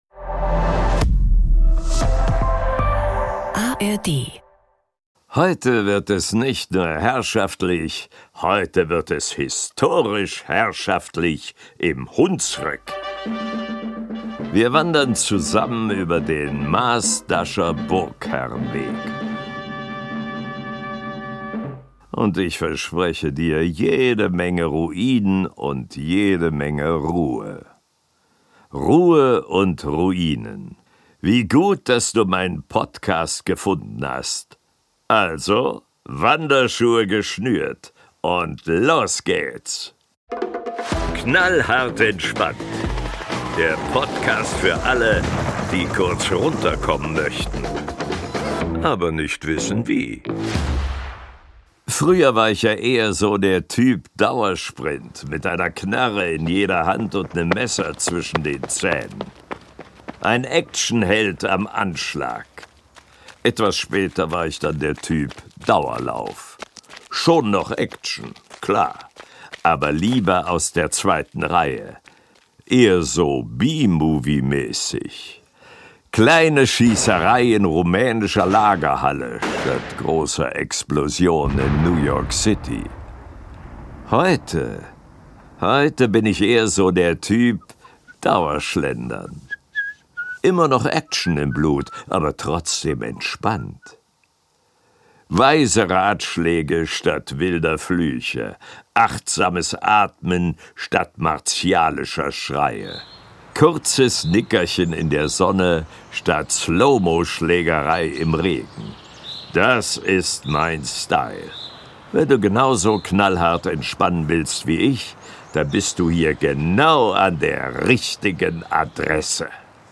Es geht in den Hunsrück, wie immer ganz entspannt und gelassen. Achtsames Atmen statt martialischer Schreie, kurzes Nickerchen in der Sonne, statt Slow-Mo-Schlägerei im Regen. Einfach zurücklehnen und der deutschen Stimme von Hollywood-Star Samuel L. Jackson lauschen.